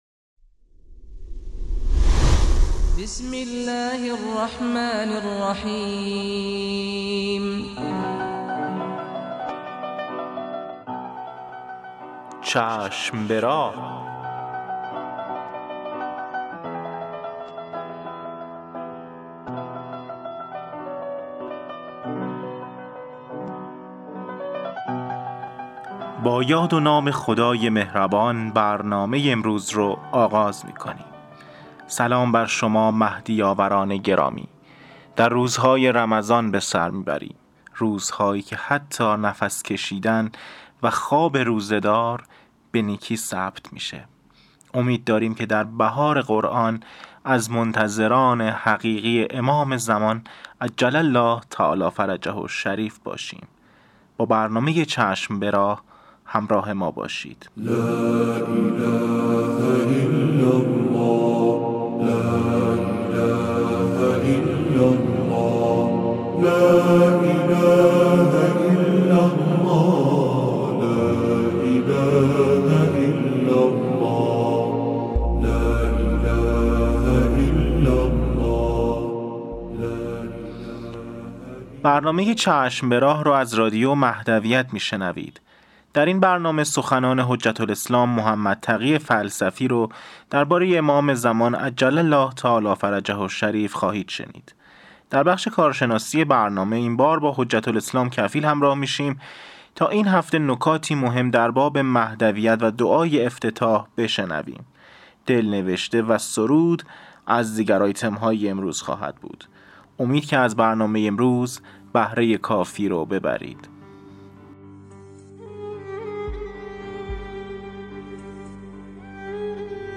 قسمت صد و هفتادم مجله رادیویی چشم به راه - بنیاد فرهنگی حضرت مهدی موعود(عج)